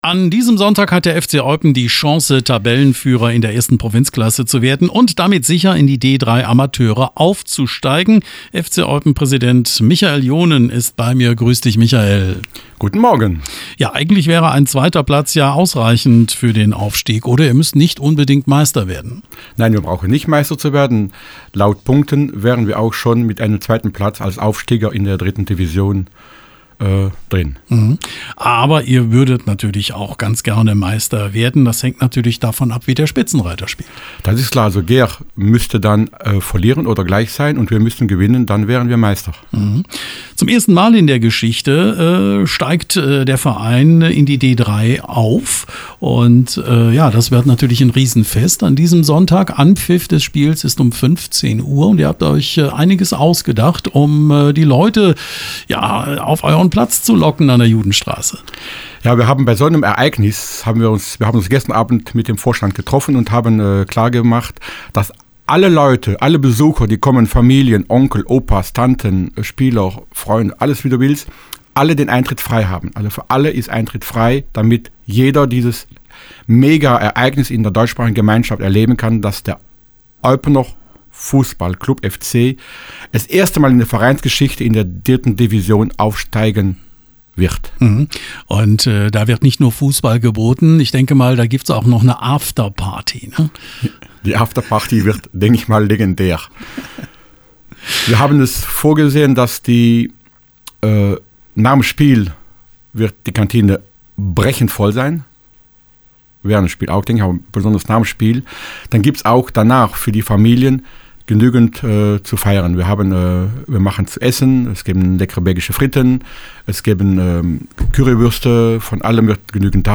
Radio Contact